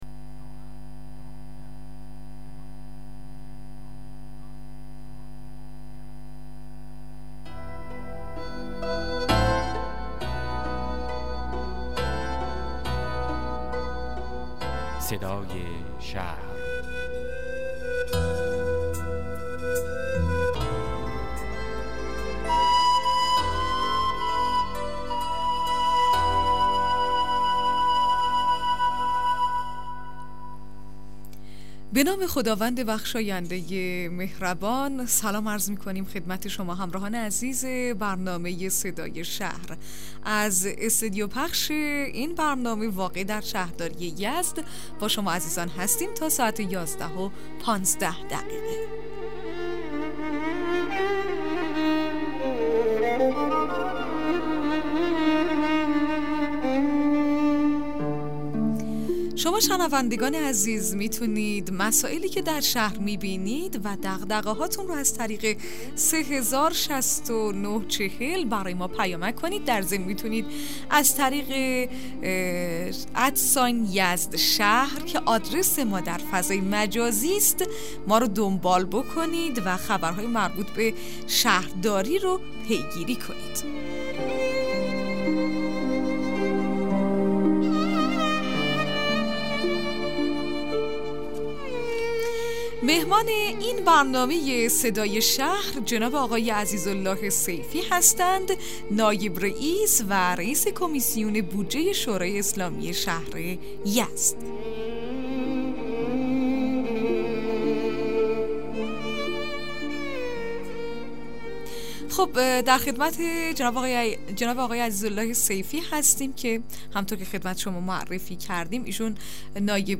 مصاحبه رادیویی برنامه صدای شهر با حضورعزیز اله سیفی رییس کمیسیون بودجه شورای اسلامی شهر یزد